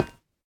sounds / block / iron / step1.ogg